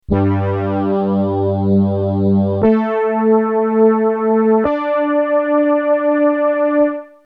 casio-sk-1-keyboard-three-notes-9759.mp3